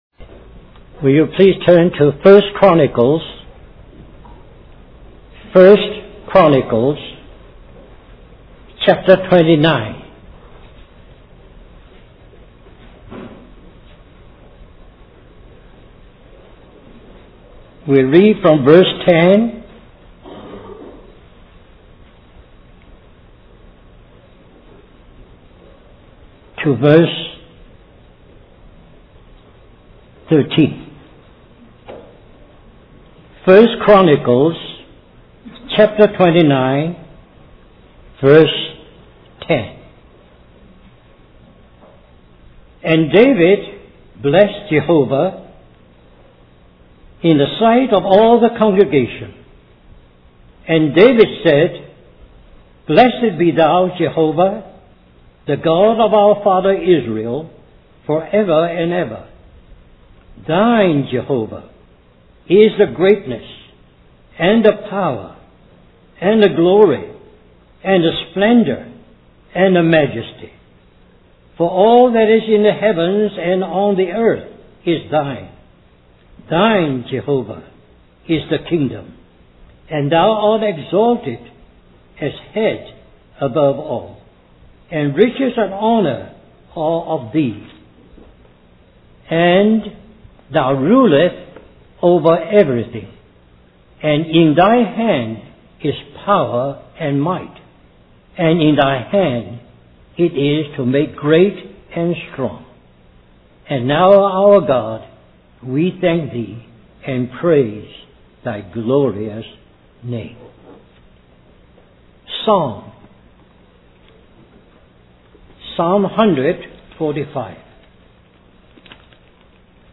In this sermon, the preacher emphasizes that all people are born into the kingdom of darkness, separated from God and without hope.